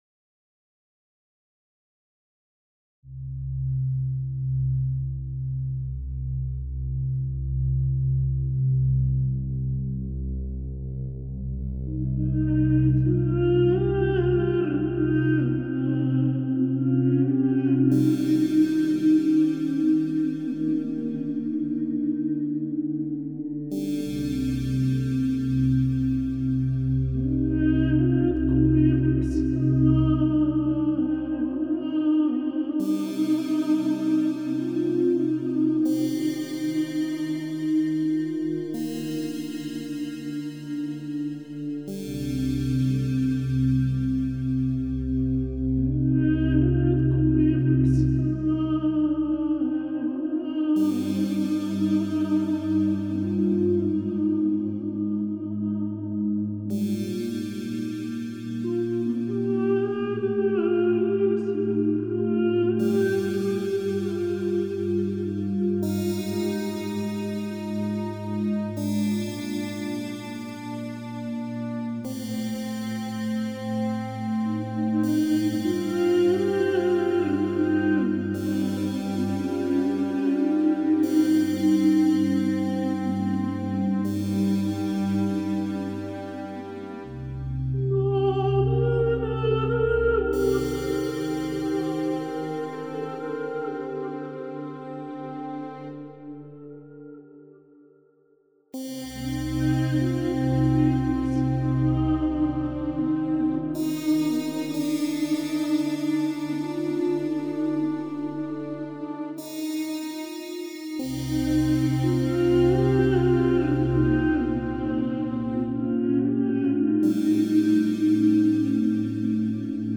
Ein Versuch das Traurige, Leidvolle klanglich darzustellen.